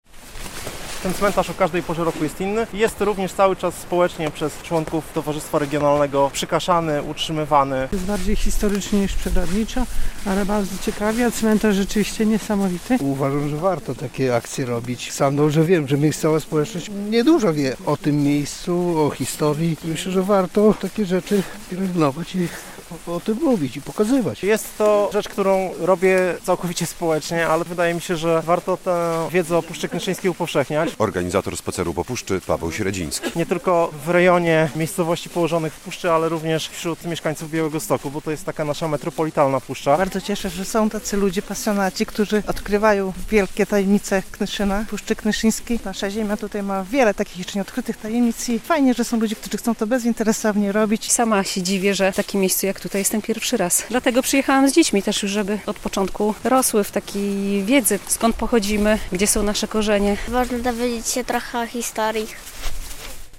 Spacer po Puszczy Knyszyńskiej - relacja